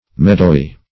Search Result for " meadowy" : The Collaborative International Dictionary of English v.0.48: Meadowy \Mead"ow*y\, a. Of or pertaining to meadows; resembling, or consisting of, meadow.